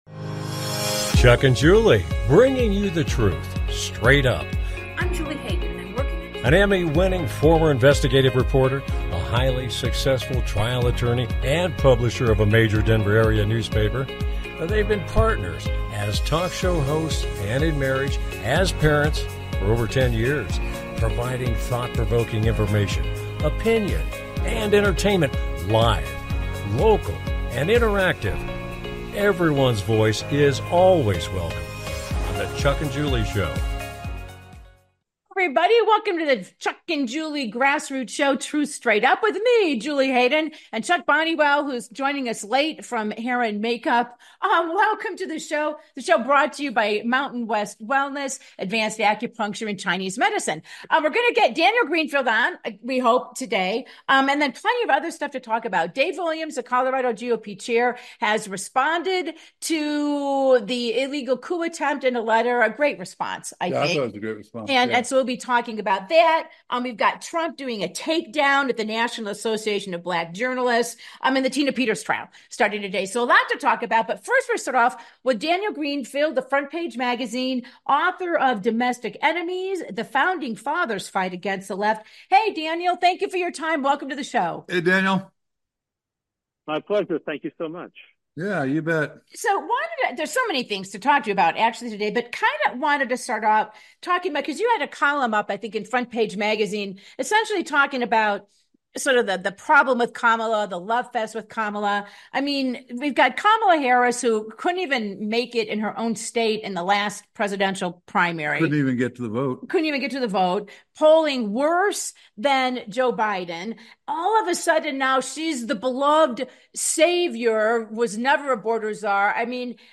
With Guest